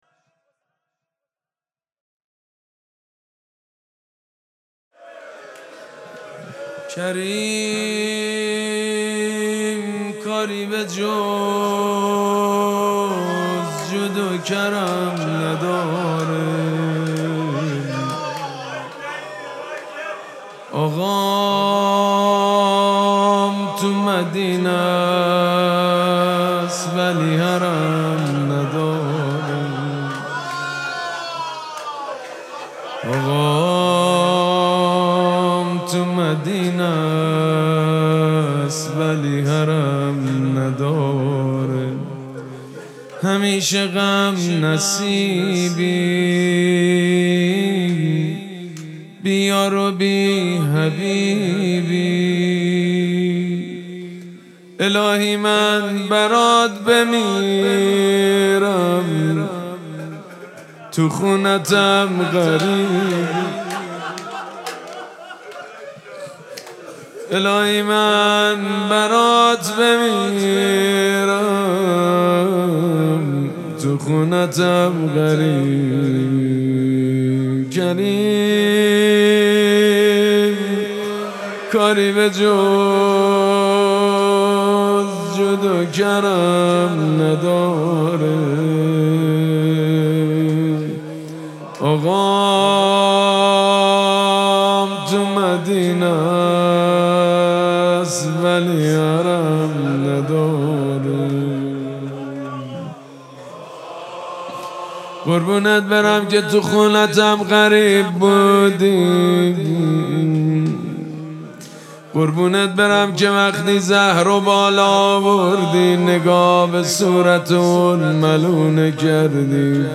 مراسم جشن شب ولادت امام حسن مجتبی(ع)
حسینیه ریحانه الحسین سلام الله علیها
شعر خوانی
حاج سید مجید بنی فاطمه